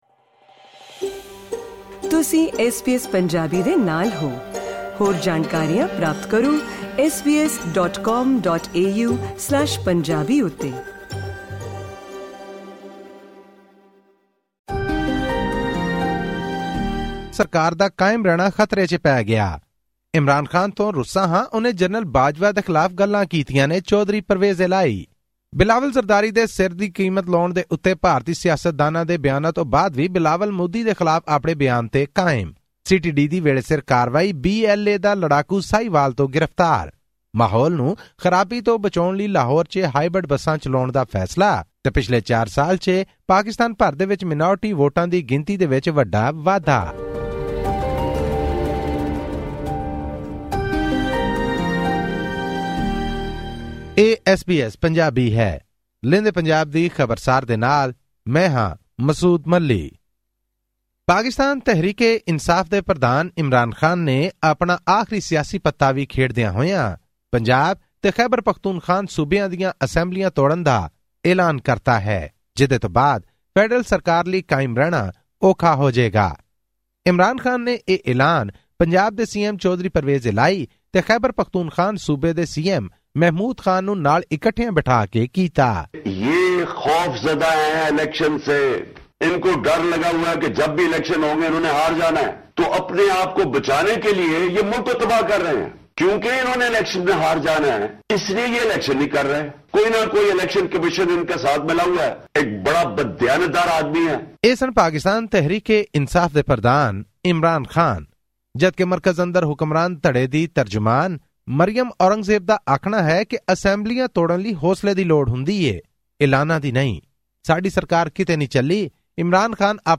ਪਾਕਿਸਤਾਨ ਦੇ ਸਾਬਕਾ ਪ੍ਰਧਾਨ ਮੰਤਰੀ ਇਮਰਾਨ ਖਾਨ ਨੇ ਐਲਾਨ ਕੀਤਾ ਹੈ ਕਿ ਮੌਜੂਦਾ ਸਰਕਾਰ 'ਤੇ ਦਬਾਅ ਬਣਾਉਣ ਲਈ ਉਨ੍ਹਾਂ ਦੀ ਪਾਰਟੀ ਨਿਰਧਾਰਤ ਸਮੇਂ ਤੋਂ ਪਹਿਲਾਂ ਚੋਣਾਂ ਜਲਦੀ ਕਰਵਾਉਣ ਦੀ ਕੋਸ਼ਿਸ਼ ਵਿੱਚ, ਇਸ ਹਫਤੇ ਦੋ ਸੂਬਾਈ ਅਸੈਂਬਲੀਆਂ ਨੂੰ ਭੰਗ ਕਰ ਦੇਵੇਗੀ। ਇਹ ਤੇ ਪਾਕਿਸਤਾਨ ਦੀਆਂ ਹੋਰ ਹਫਤਾਵਾਰੀ ਖਬਰਾਂ ਦੀ ਤਫਸੀਲ ਜਾਨਣ ਲਈ ਸੁਣੋ ਇਹ ਖਾਸ ਰਿਪੋਰਟ...